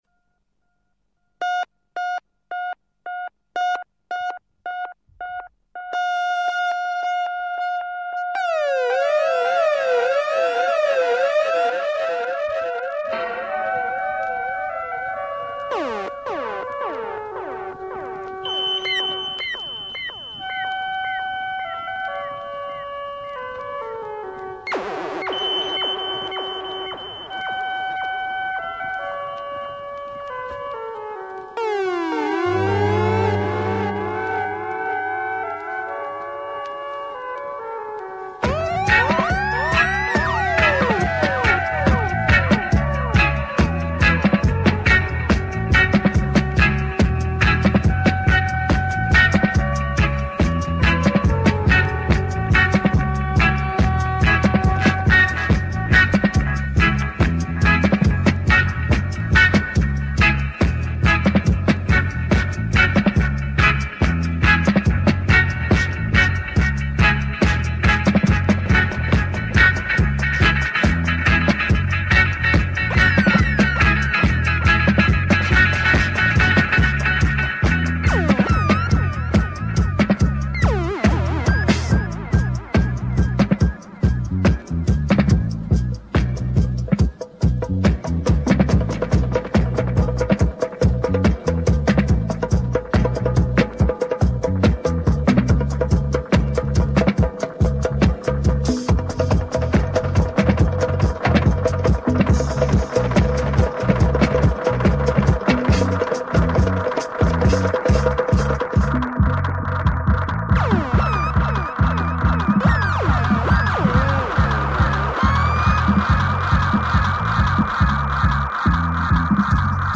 All vinyl and 1 acetate, no cd buisness.